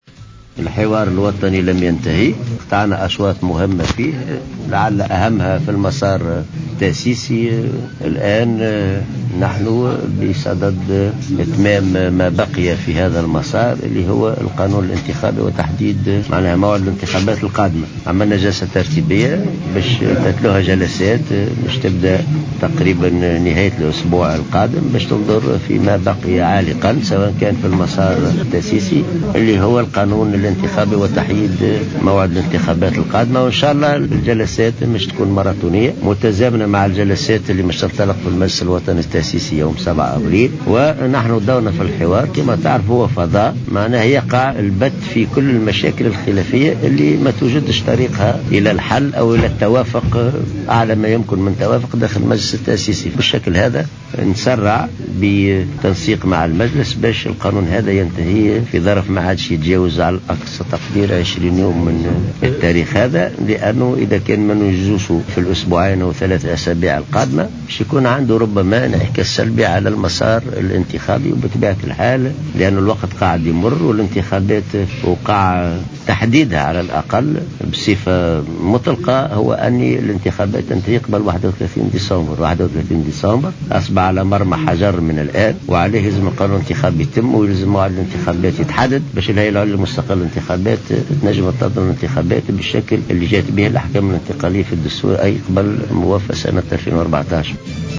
ودعا العباسي في تصريح إعلامي إلى تسريع نسق الحوار الوطني لتجاوز كافة النقاط الخلافية وأبرزها مسألة القانون الانتخابي في فترة لا تتجاوز 20 يوما محذرا من الانعكاسات السلبية على المسار الانتخابي في حال تأخر حسم الخلافات.